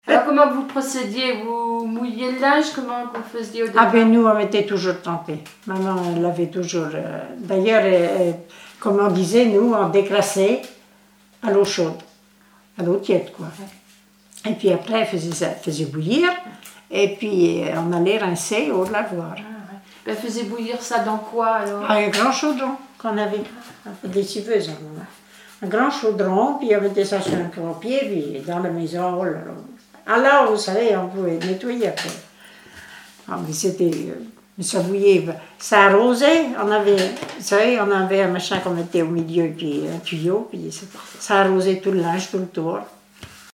Témoignages sur les tâches ménagères
Catégorie Témoignage